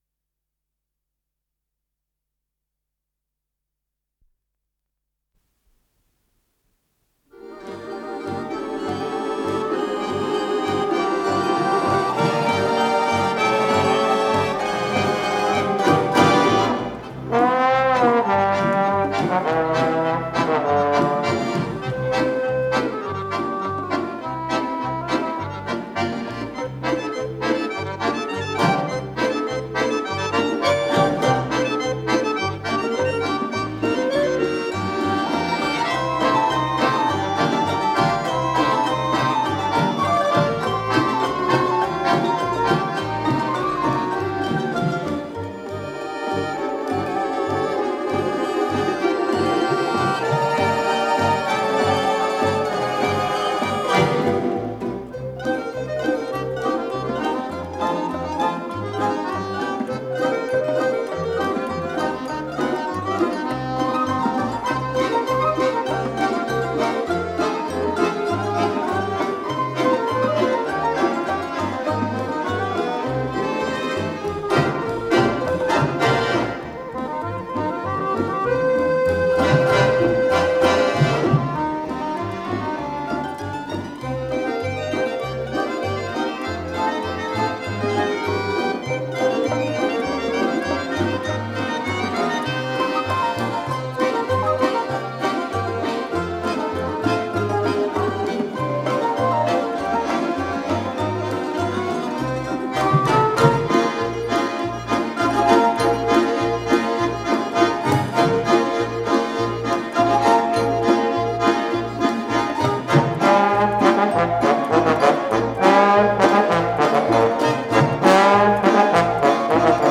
с профессиональной магнитной ленты
труба + ударные
ВариантДубль моно